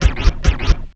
DDW Scratch.wav